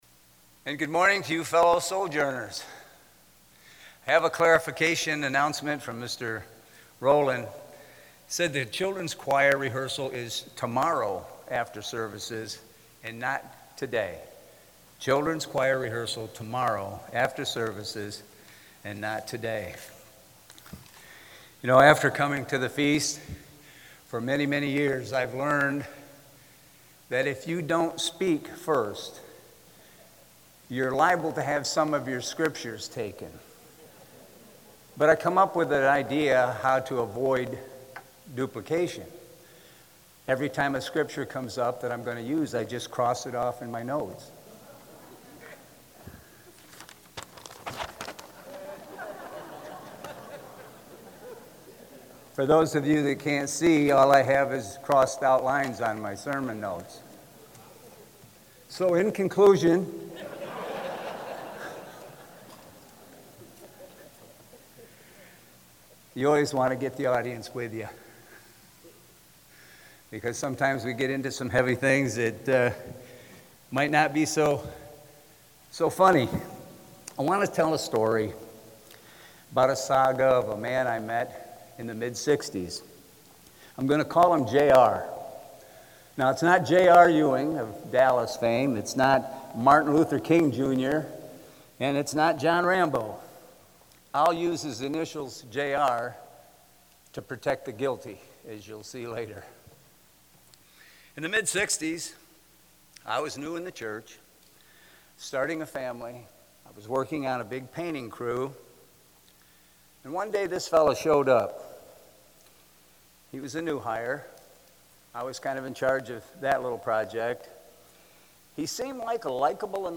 Sermon given during the Feast of Tabernacles in Ocean City, Maryland.
This sermon was given at the Ocean City, Maryland 2023 Feast site.